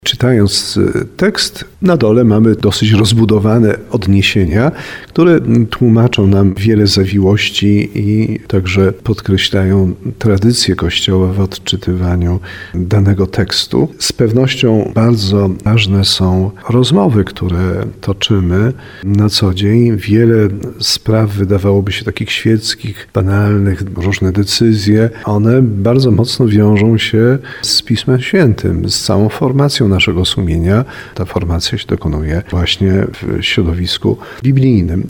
Biskup diecezjalny w audycji 'W trosce o Kościół’ mówił o roli biblijnych komentarzy.